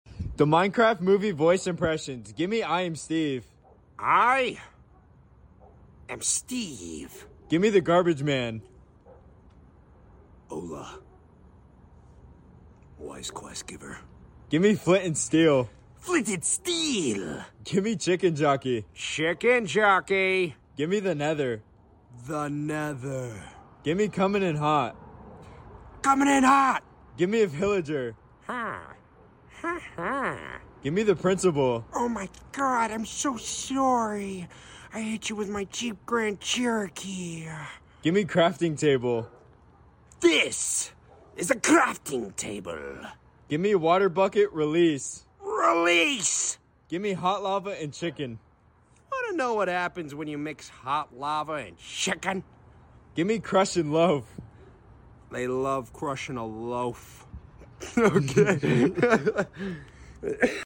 A Minecraft movie voice impressions sound effects free download